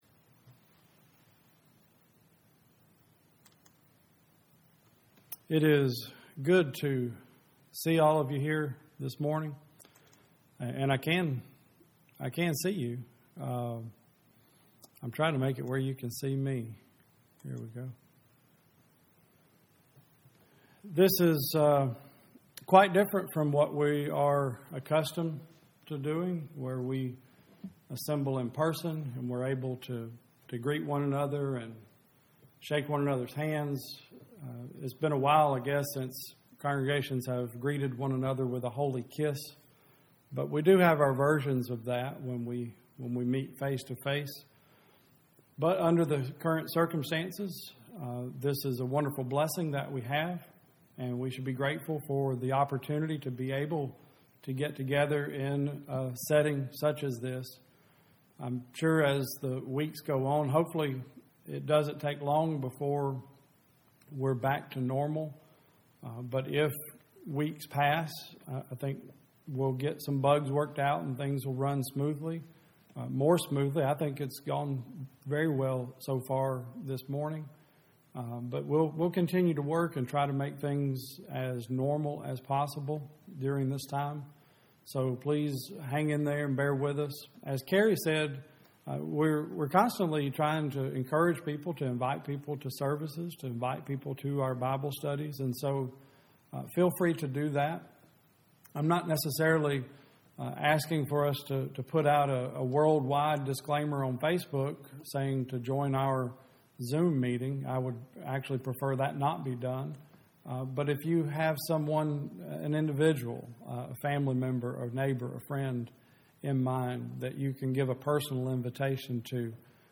2020 Service Type: Sunday Service Preacher